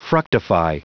Prononciation du mot fructify en anglais (fichier audio)
Prononciation du mot : fructify